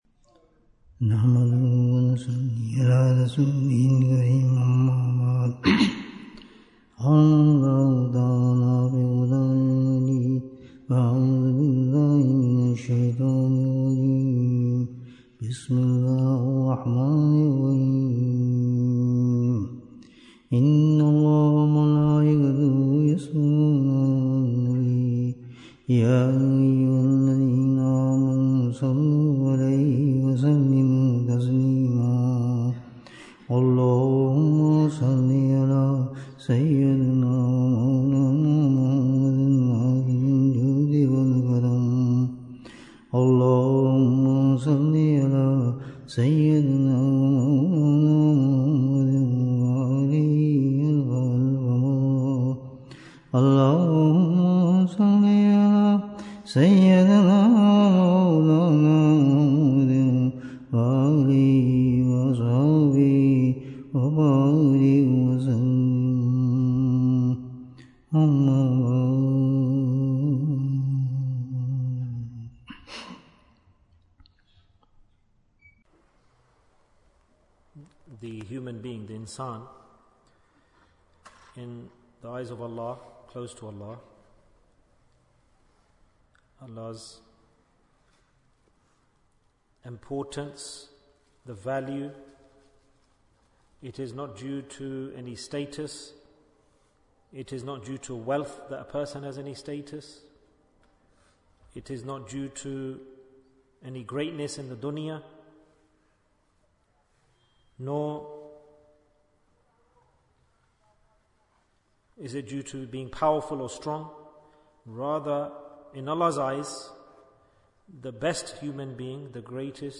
How Can We Judge Our Iman? Bayan, 38 minutes9th May, 2024